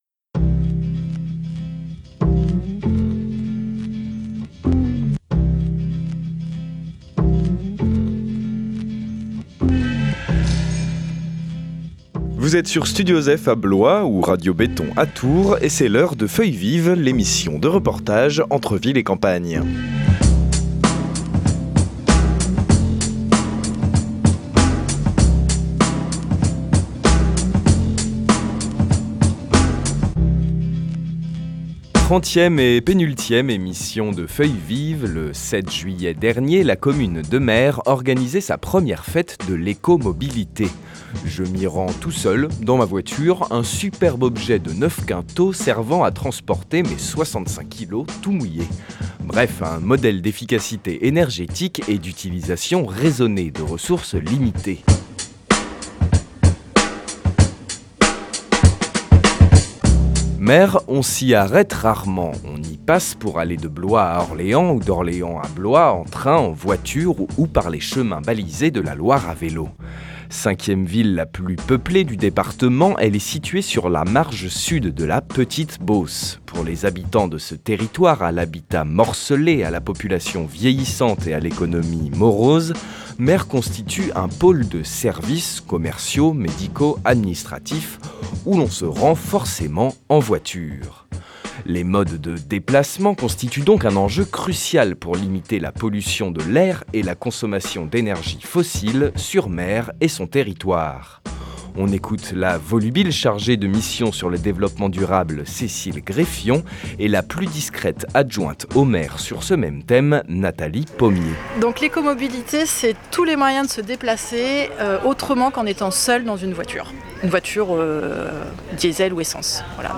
Avant de se rendre dans les jardins du collectif des Métairies pour un plateau sur l’agriculture urbaine, reportage à la première édition de la Fête de l’écomobilité, qui s’est déroulée à Mer le 7 juillet dernier. Au menu : atelier de réparation de vélos, balades en ville sur le thème des noms de rues, essai de véhicules électriques.